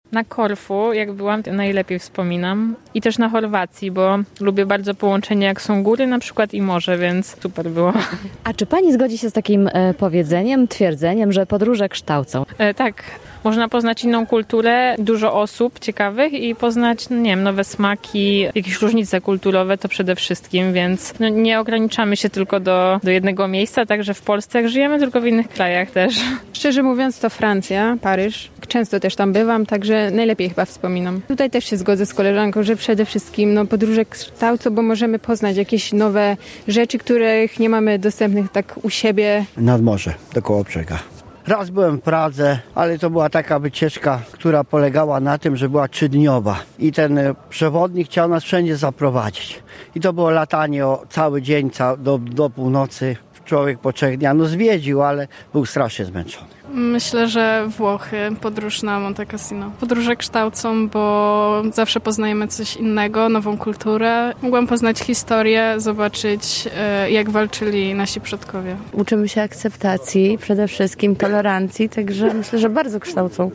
Czy lubimy podróżować? Weekednowa sonda